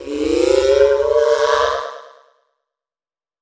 Techno / Voice